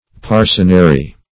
Parcenary \Par"ce*na*ry\, n. [See Parcener, partner.] (Law)